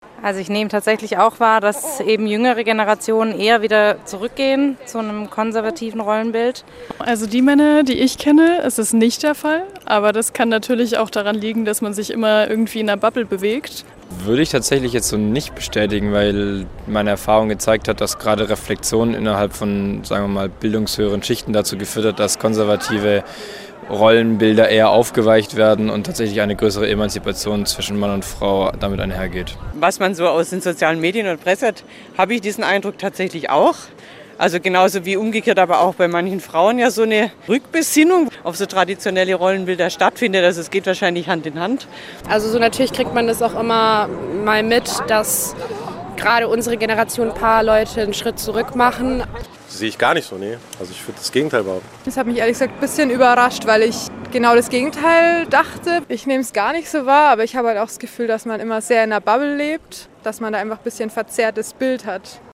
Radio- und Video-Umfrage in der Freiburger Innenstadt im Rahmen des BOGY-Praktikums im SWR Studio Freiburg.